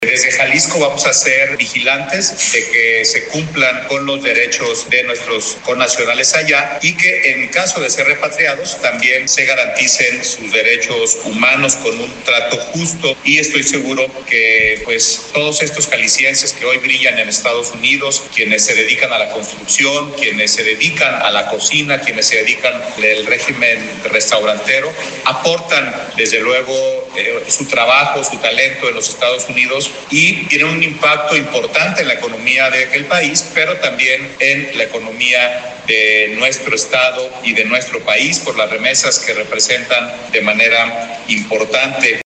Debido a las redadas que ha implementado el gobierno de Estados Unidos autoridades de Jalisco anunciaron una estrategia para atender a migrantes jaliscienses que vivan en el país vecino el secretario general de gobierno Salvador Zamora explicó que este año han sido repatriados menos jaliscienses de enero a junio tienen el registro de 2223 el año pasado fueron 4246 sin embargo atenderán a quien lo necesite